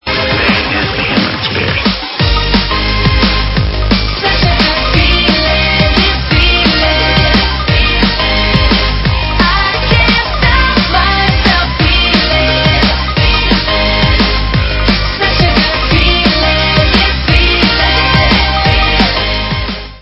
sledovat novinky v oddělení Dance/Drum & Bass